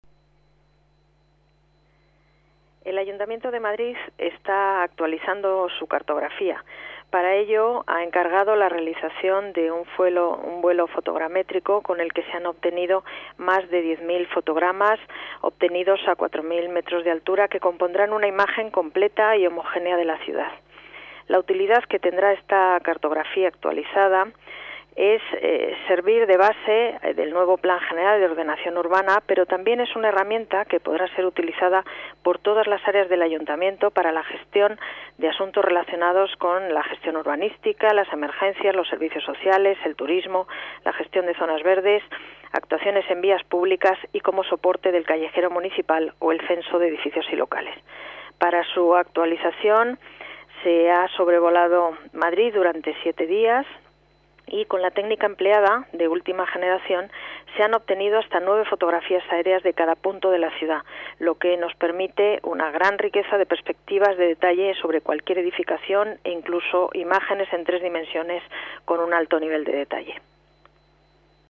Nueva ventana:Declaraciones de Paz González, delegada de Urbanismo y Vivienda